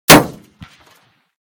/ gamedata / sounds / material / bullet / collide / metall05gr.ogg 16 KiB (Stored with Git LFS) Raw History